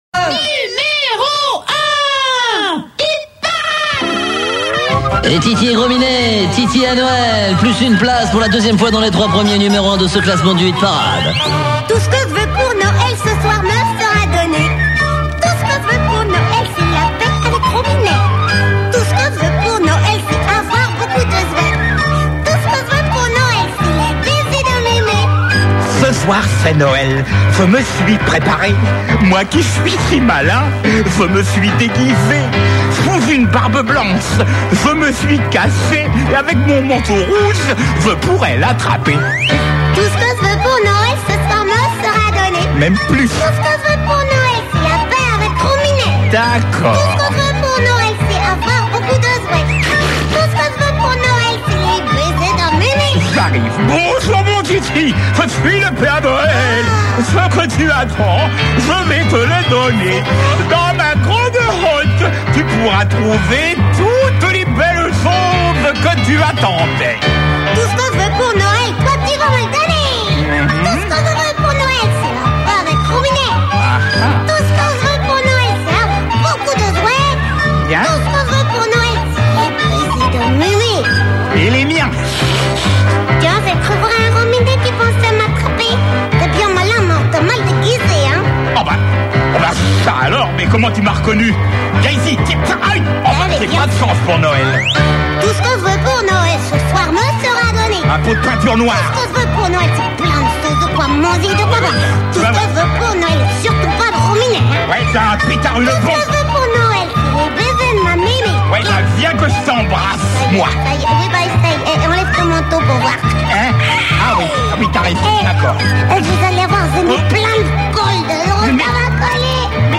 Extraits de l'émission de radio qui a duré de 1994 à 2001 sur Fun Radio sous des noms différents.